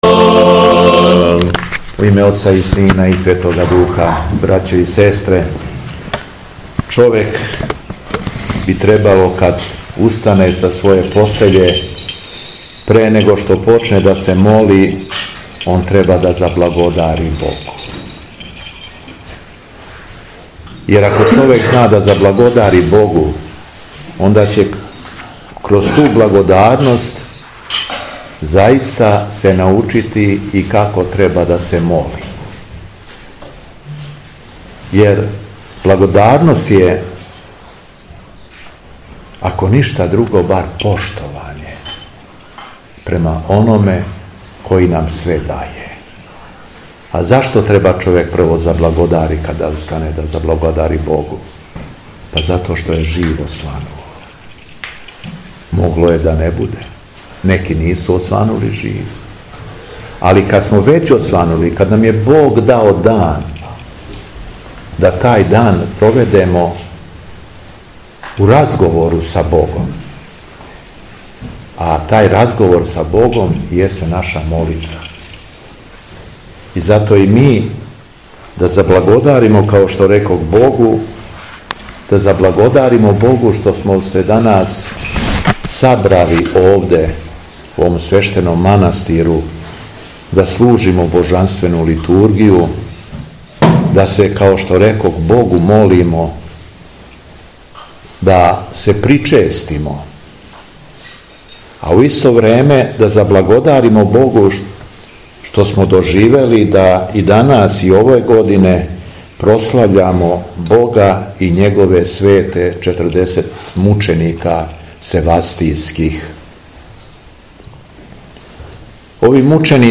У суботу 22. марта 2025. године, када прослављамо Светих 40 мученика севастијских (Младенци), Његово Високопреосвештенство Митрополит шумадијски Г. Јован служио је Свету Архијерејску Литургију у манастиру Никољу.
Беседа Његовог Високопреосвештенства Митрополита шумадијског г. Јована
Након прочитаног јеванђеља беседио је владика Јован: